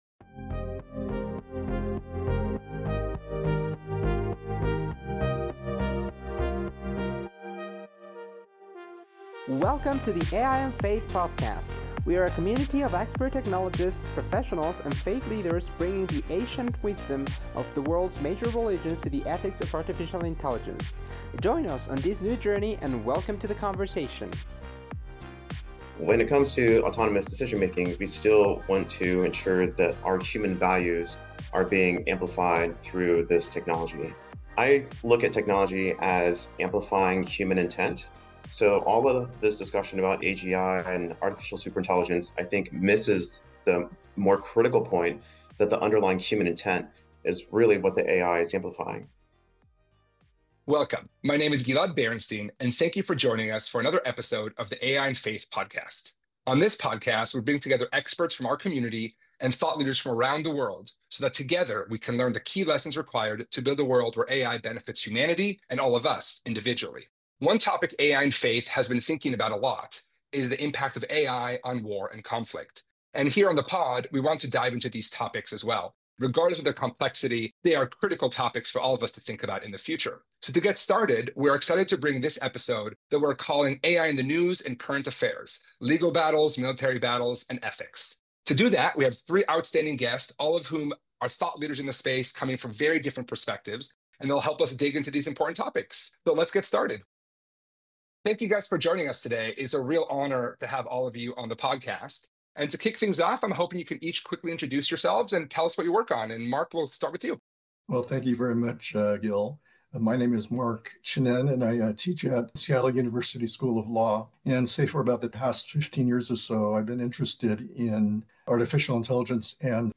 In this wide ranging conversation, we dive into the impacts of AI on war, conflict, and the law. We explore how the ethics of war may change due to the rise of artificial intelligence, and of course what we can learn from our various religions and religious traditions to help us act ethically as we move into this future.